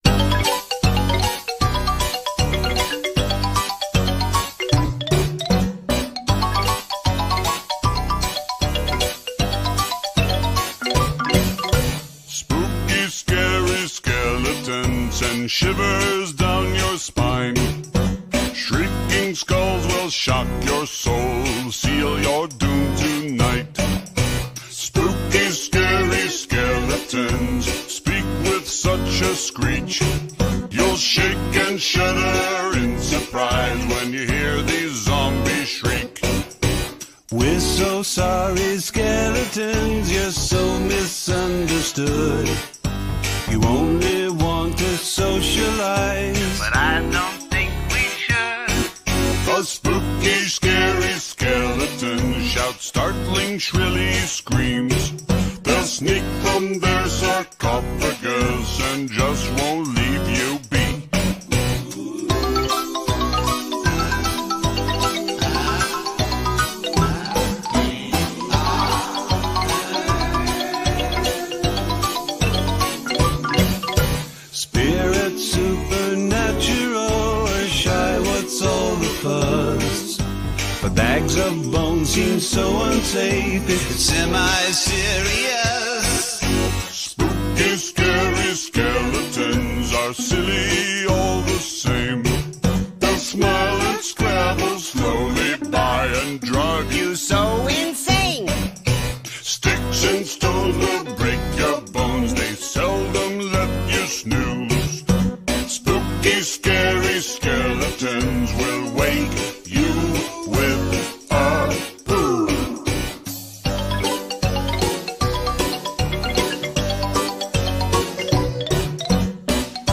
BPM77
Audio QualityPerfect (High Quality)